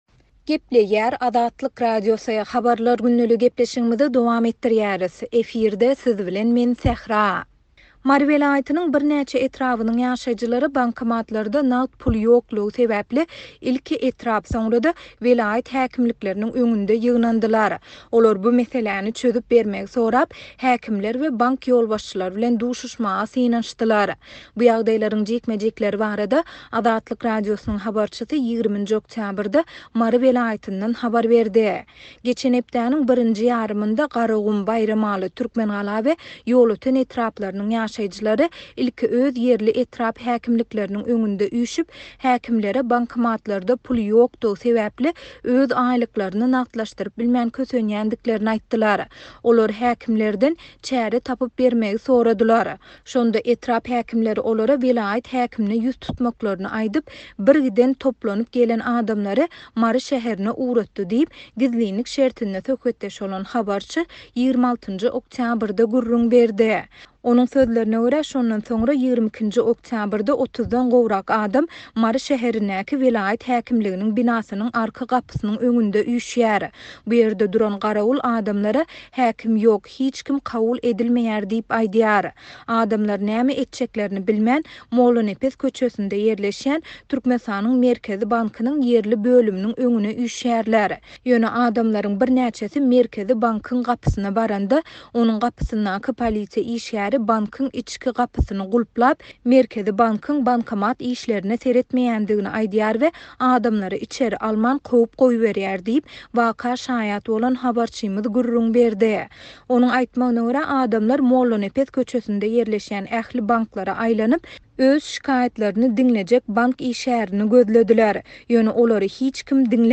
Bu ýagdaýlaryň jikme-jikleri barada Azatlyk Radiosynyň habarçysy 20-nji oktýabrda Mary welaýatyndan habar berdi.